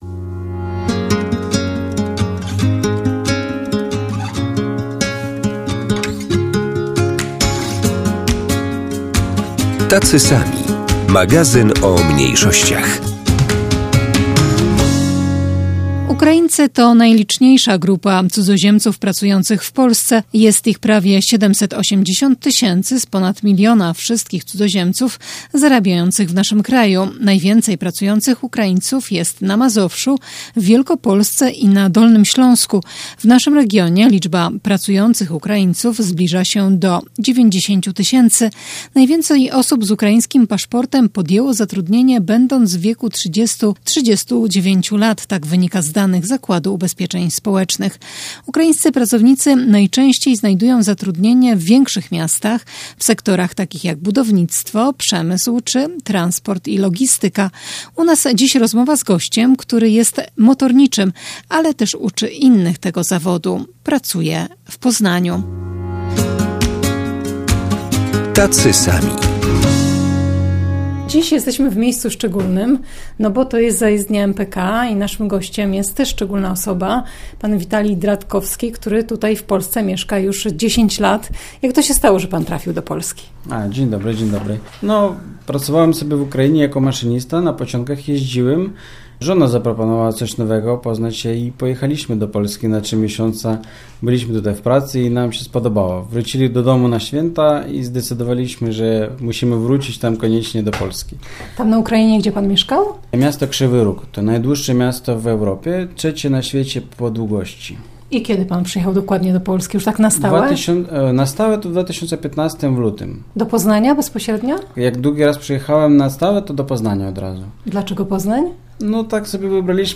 Dziś w programie rozmowa z Ukraińcem, który od 10 lat mieszka i pracuje w Poznaniu.